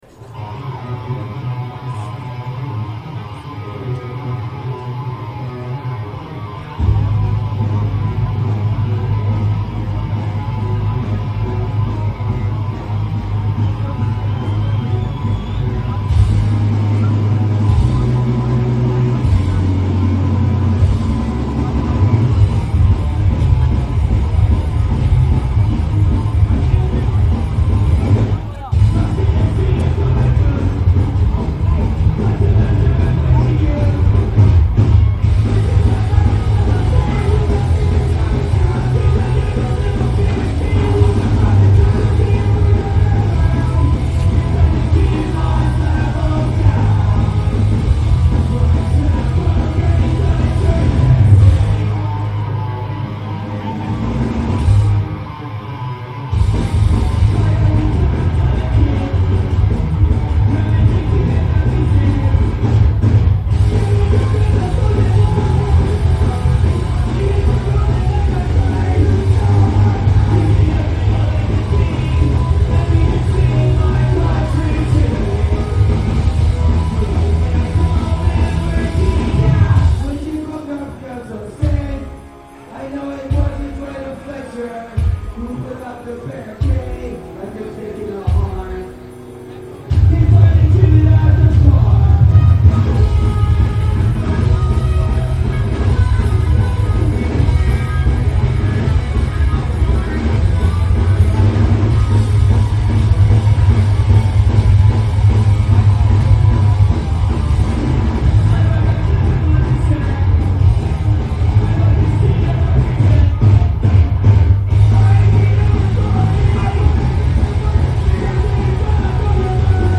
Recorder: Sharp IM-DR420H (LP4-Mode)
Microphone: Sony ECM-T6 (Mono)